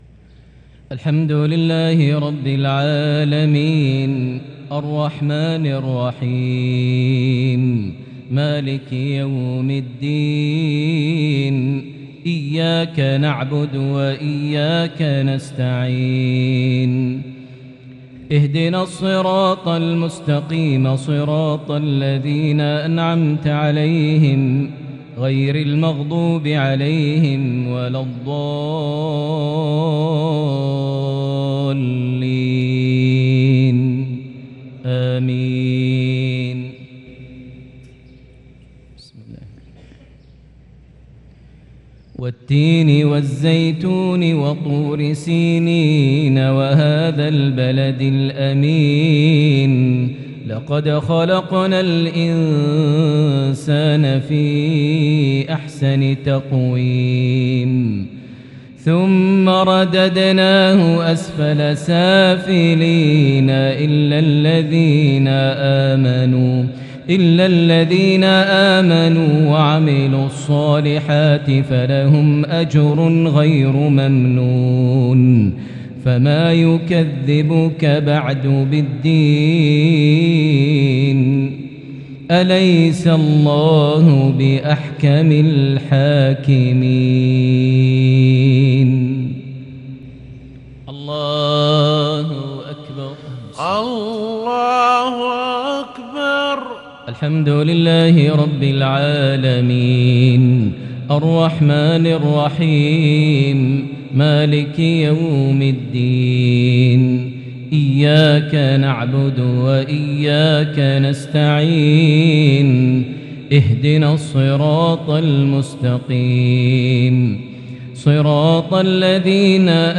maghrib 1-7-2022 prayer from Surah At-Tin + Surah Quraish > 1443 H > Prayers - Maher Almuaiqly Recitations